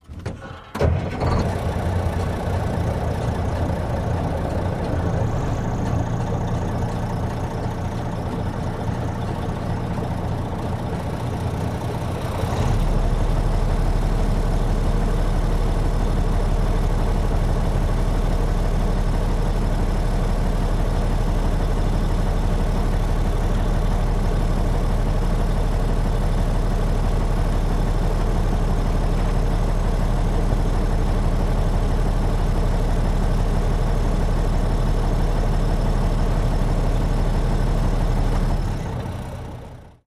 tr_macktruck_idle_01_hpx
Mack truck starts up and idles. Loop. Vehicles, Truck Idle, Truck Engine, Motor